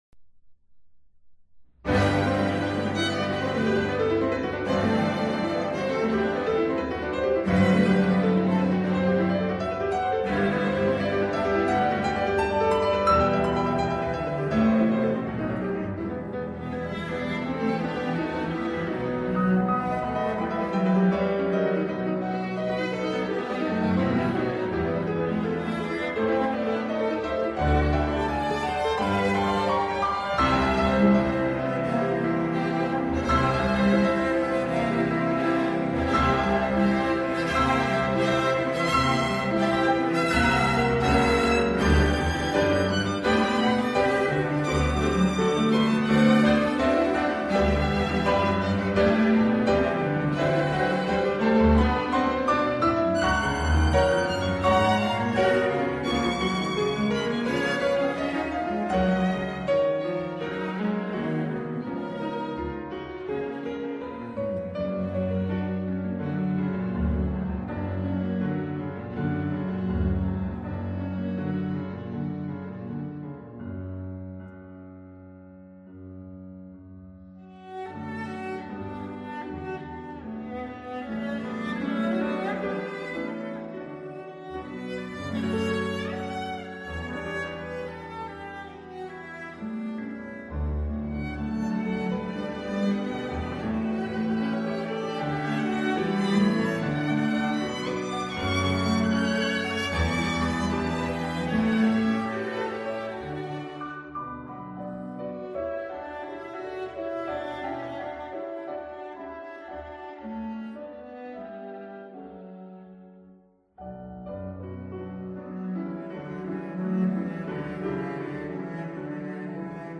Adagio (Romanza) and V. Molto allegro.